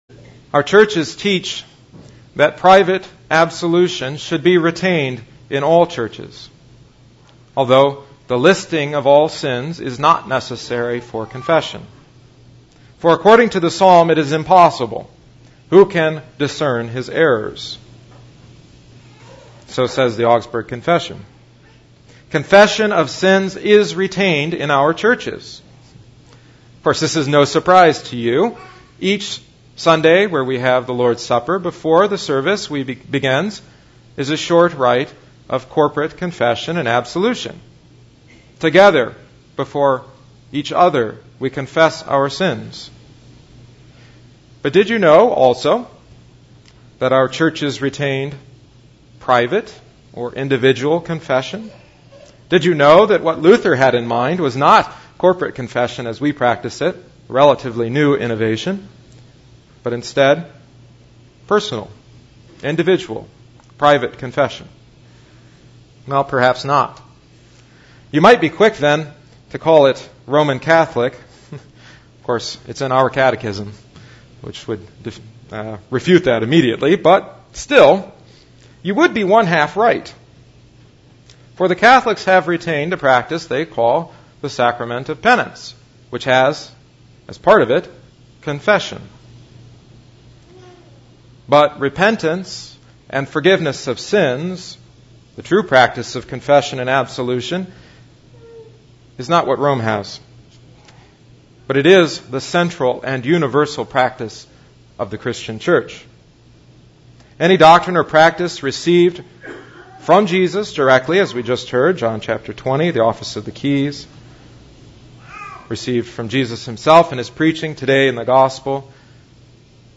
Catechetical Sermon on Confession and Absolution (Augsburg XI-XII). The audio recording also includes reflections on the commemoration of St. Augustine of Hippo, the sack of Rome, and his “City of God.”